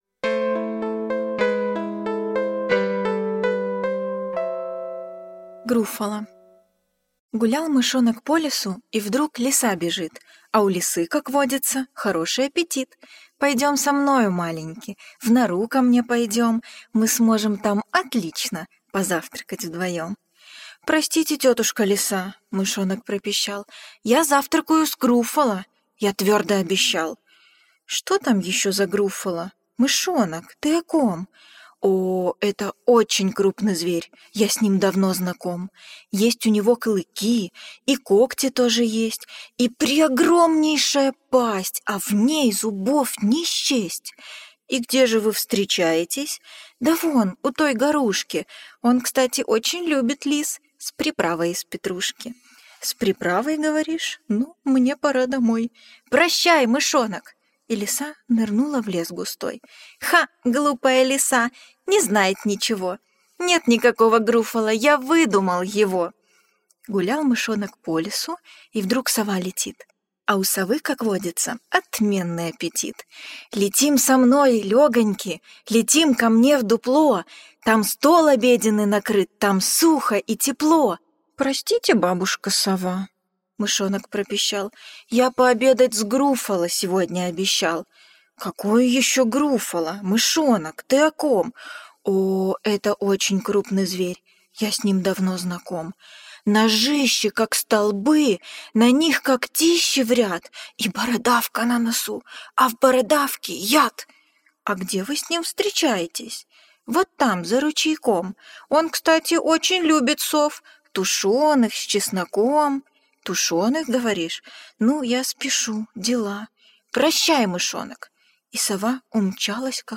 Груффало - аудиосказка Джулии Дональдсон - слушать онлайн